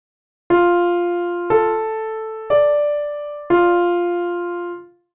Now we’ll use the full major pentatonic scale, adding the so and la notes, and the upper do’ too.
(key: F Major)